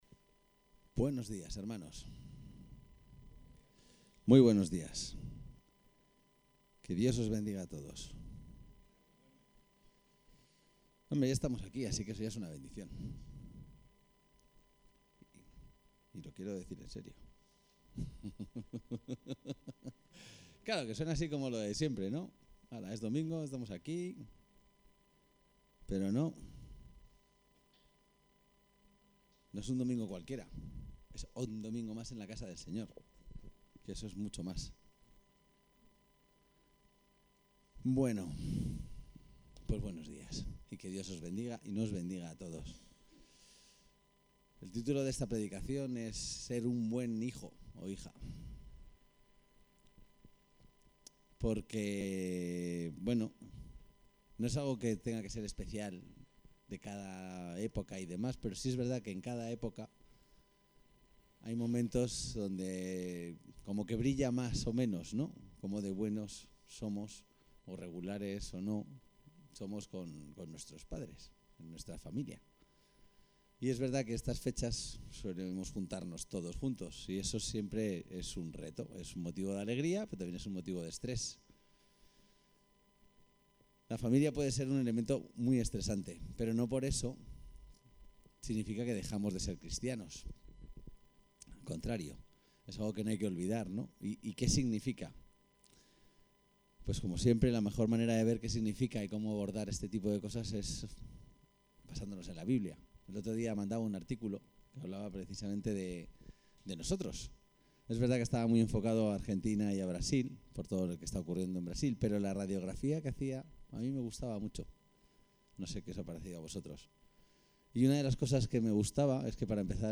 El texto de apoyo a la predicación -> Ser un buen hijo